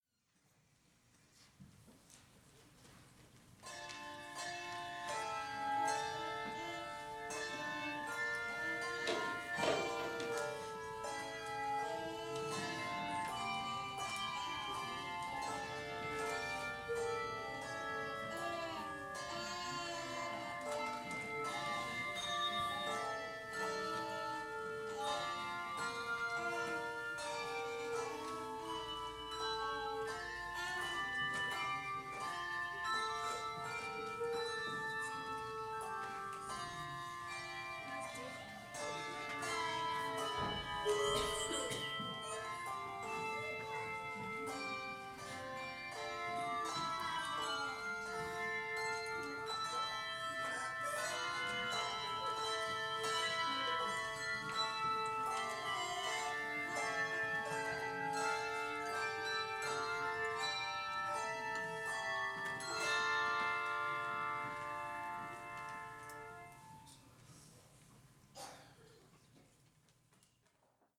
Bell Choir Stanza 4
6-bells.mp3